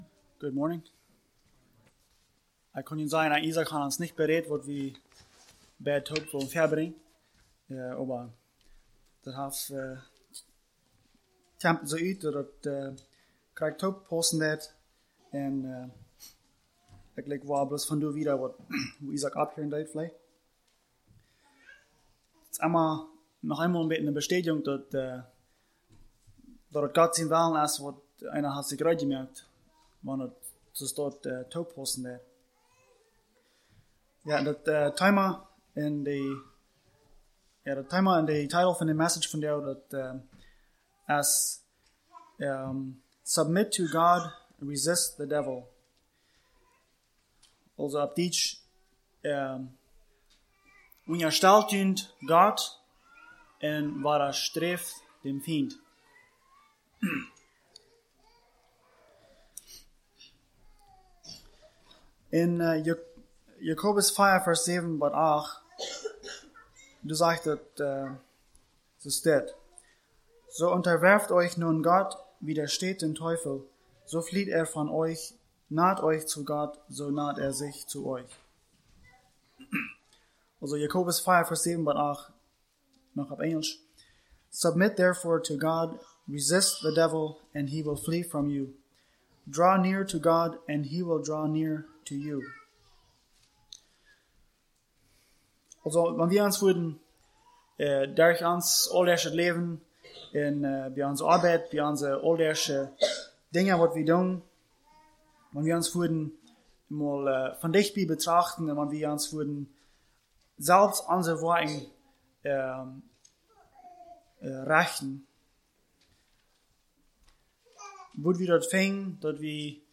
Sunday Message